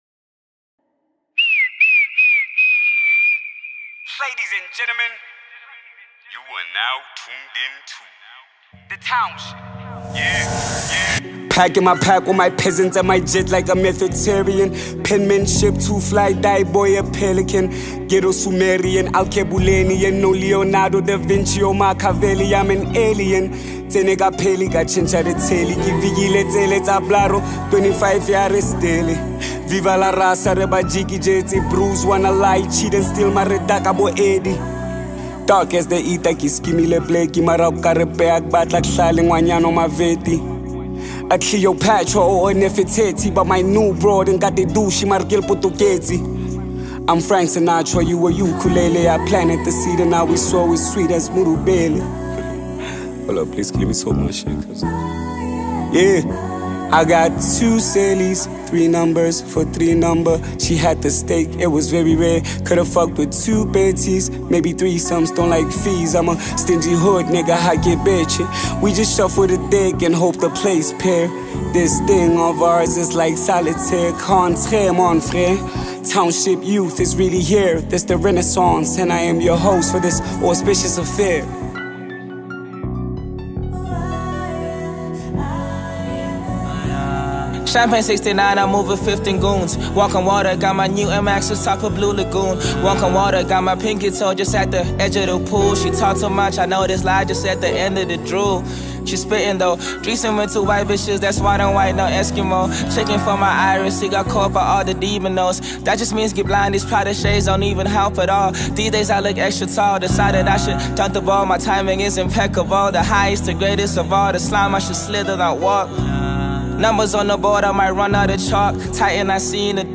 a South African rapper and music icon.